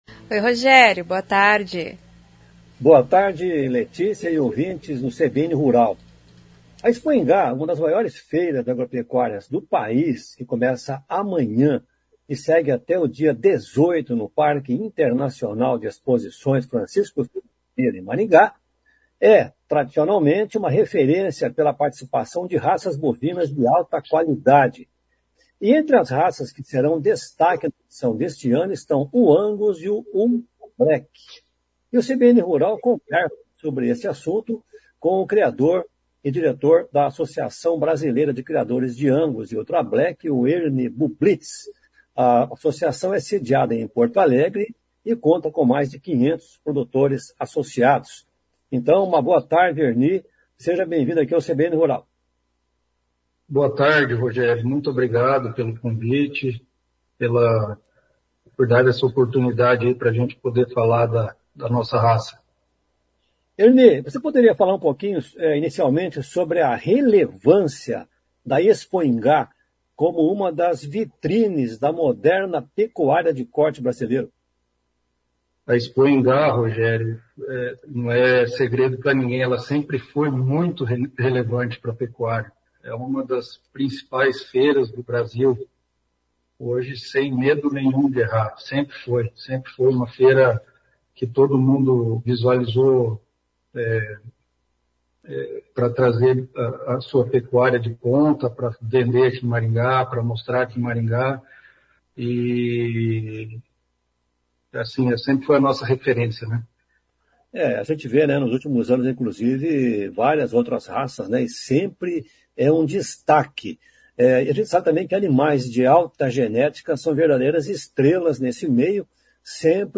conversa com o criador paranaense